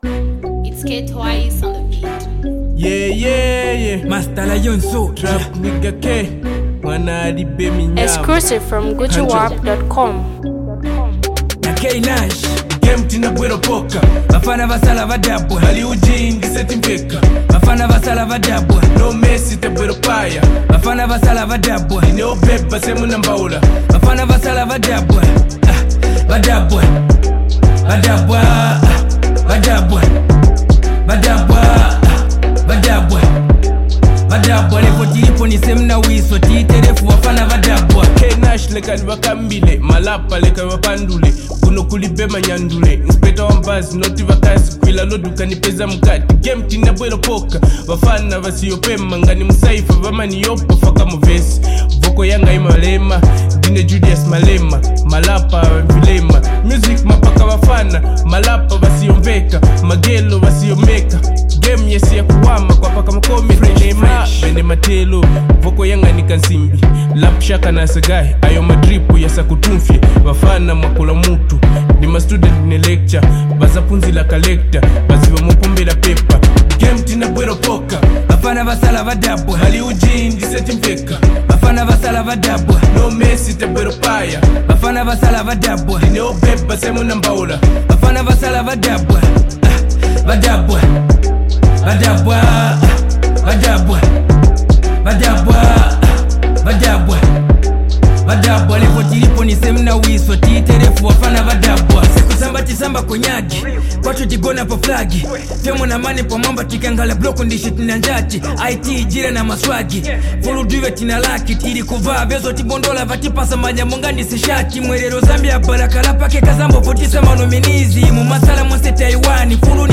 and he’s back with his latest inspirational hit
rap
uplifting, real, and straight from the heart